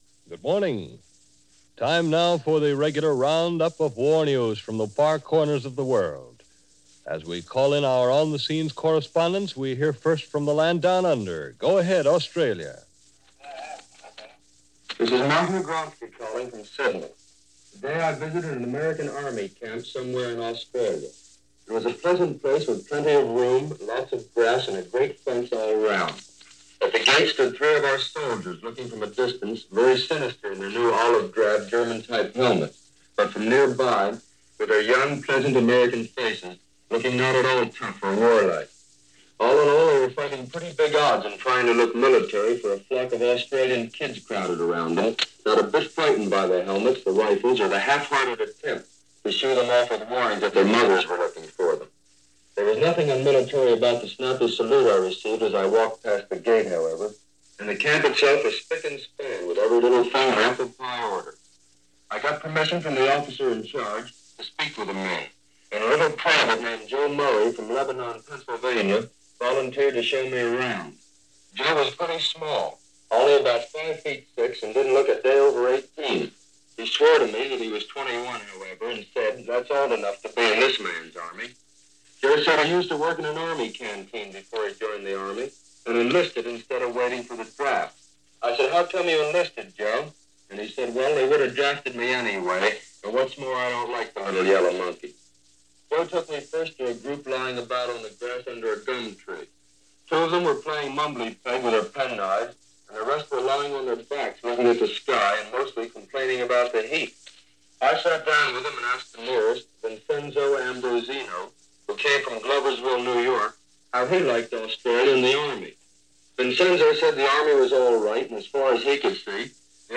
March 30, 1942 - Americans In Australia - Russian Drives West - Commando Raid At St. Nazaire - news on the war from NBC Red Network.
Roundup Of War News
This report by Martin Agronsky covers the adjustments the American troops were making to the new surroundings, along with new customs and the sudden popularity of the troops by female inhabitants of the land down-under.